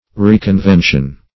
Reconvention \Re`con*ven"tion\ (-v?n"sh?n), n. (Civil Law)